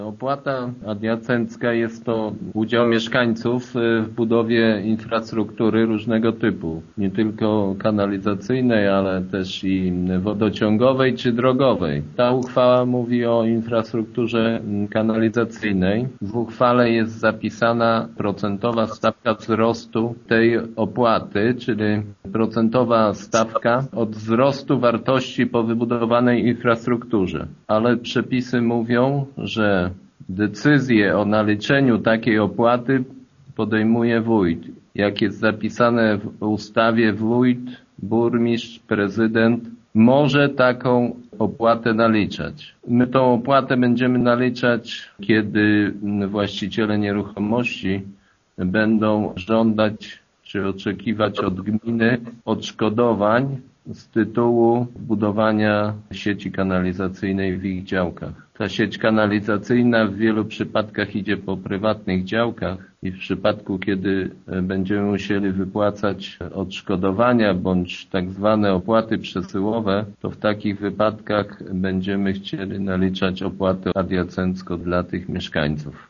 „Rada przyjęła też uchwałę w sprawie opłaty adiacenckiej z tytułu wzrostu wartości nieruchomości po wybudowaniu kanalizacji” - informuje wójt Jacek Anasiewicz: